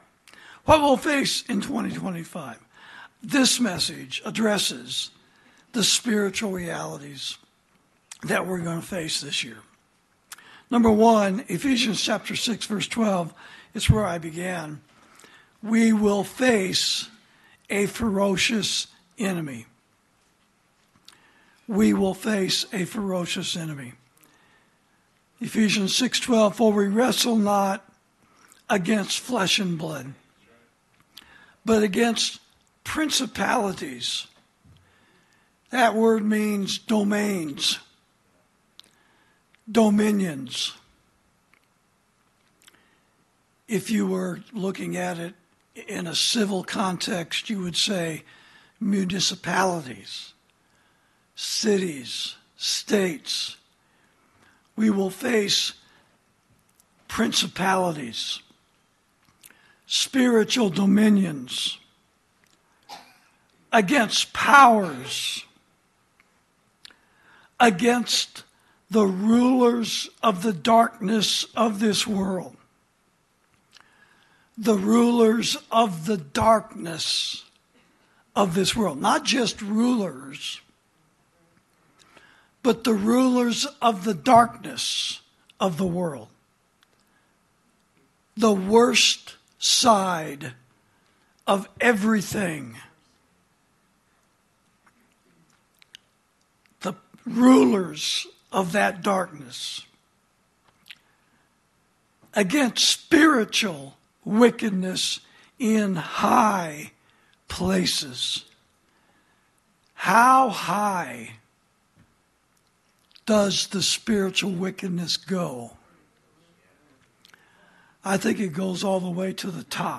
Sermons > What We Will Face in 2025